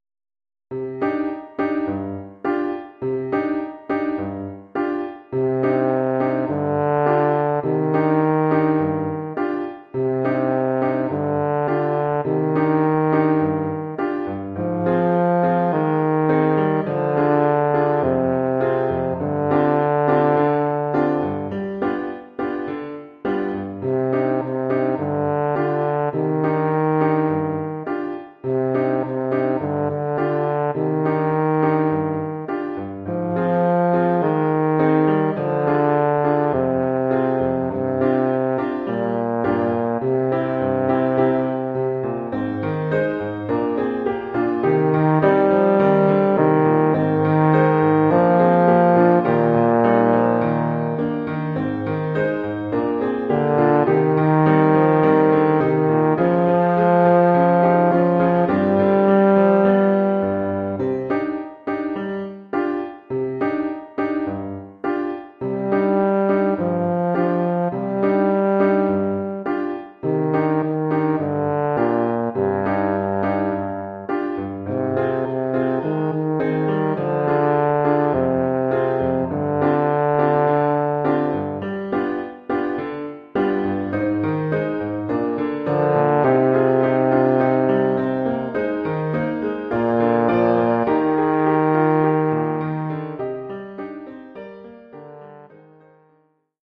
Oeuvre pour saxhorn basse et piano.
Oeuvre pour saxhorn basse / euphonium /